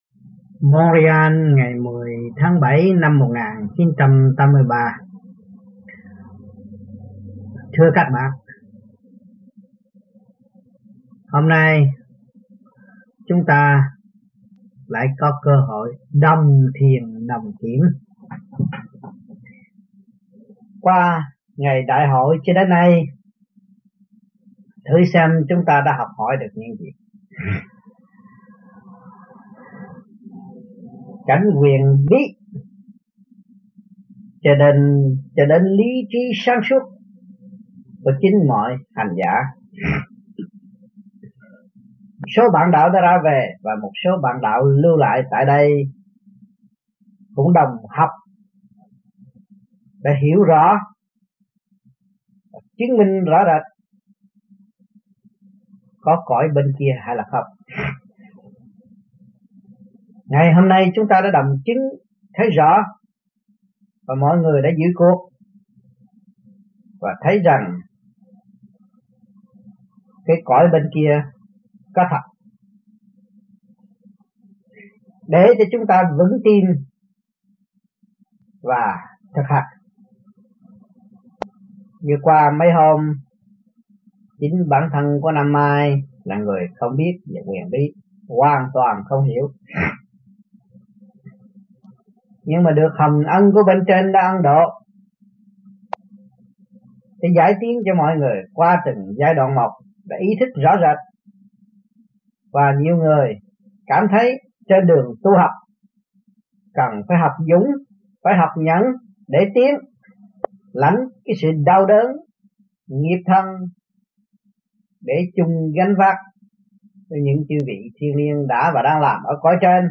Canada Trong dịp : Đại hội quốc tế >> wide display >> Downloads